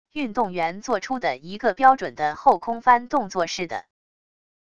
运动员做出的一个标准的后空翻动作似的wav音频